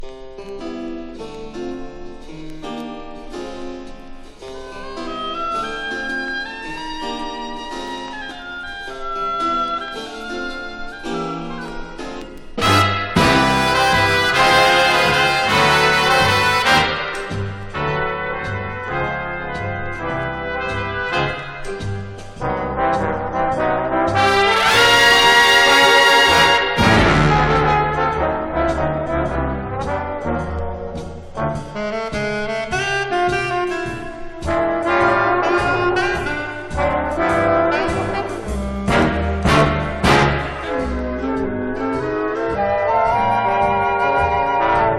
Jazz, Swing, Easy Listening　USA　12inchレコード　33rpm　Mono